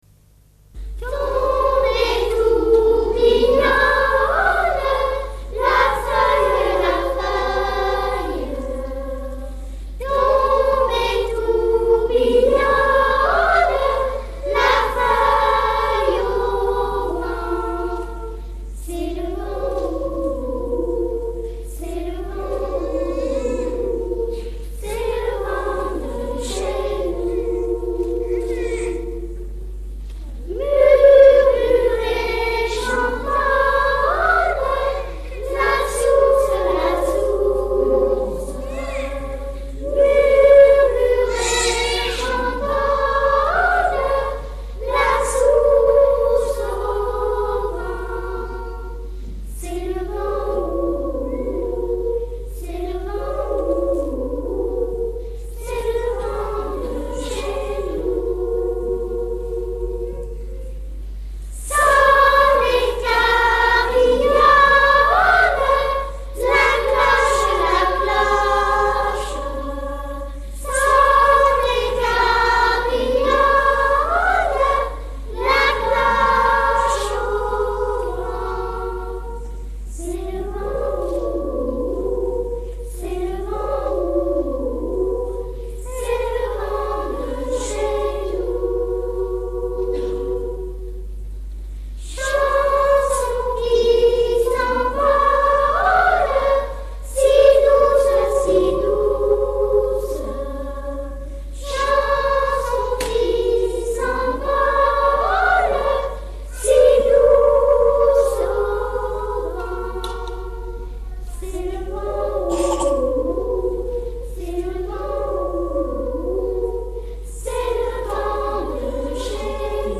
Concert Dimanche 7 Mars 1982 Eglise Romane de CHARNAY
Extraits du concert de CHARNAY